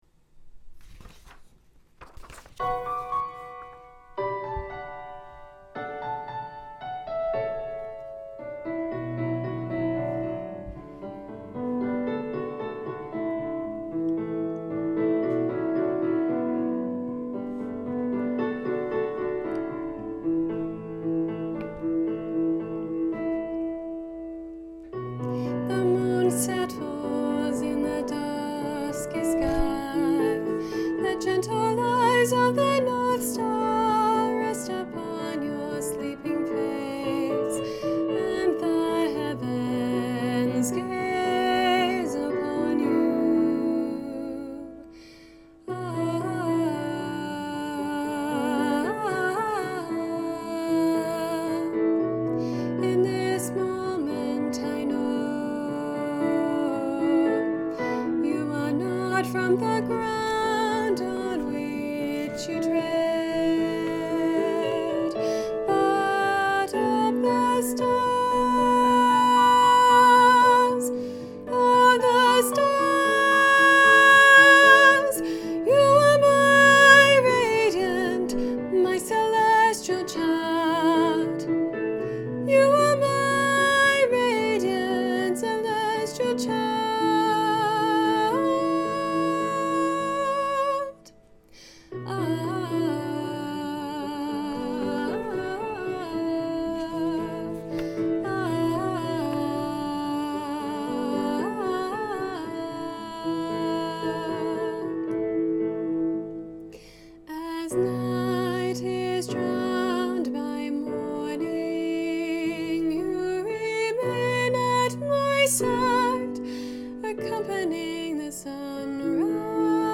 Lunar Lullaby, Jnr Alto
Junior-Choir-Lunar-Lullaby-Alto.mp3